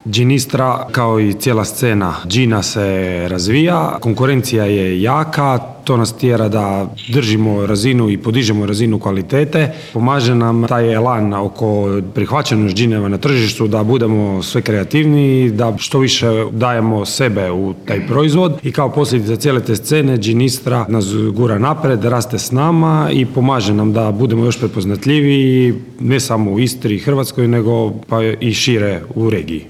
ROVINJ - Drugi GinIstra Festival sve je bliže pa je tim povodom ovog utorka u Rovinju održana konferencija za medije na kojoj je predstavljeno ovogodišnje izdanje Festivala, a koje će se upravo u Rovinju, u Staroj tvornici duhana, održati ovog vikenda - u petak i subotu.